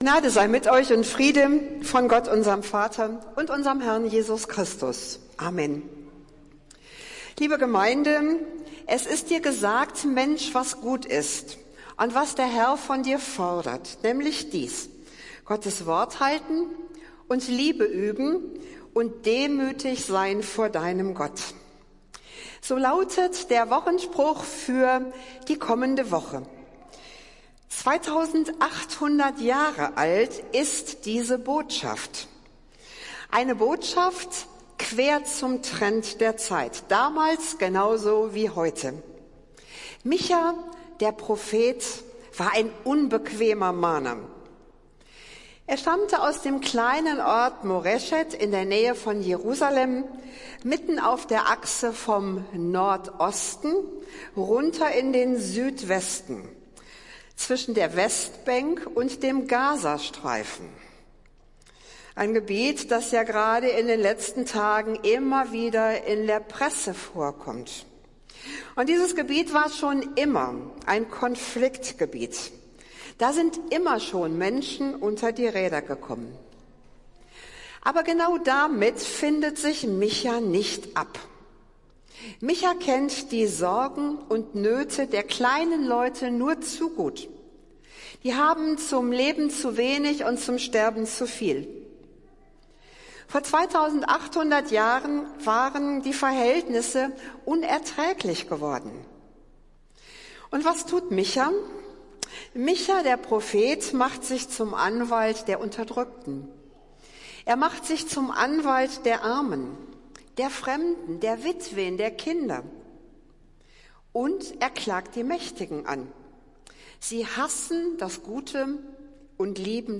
Predigt des Gottesdienstes aus der Zionskirche vom Sonntag, den 22. Oktober 2023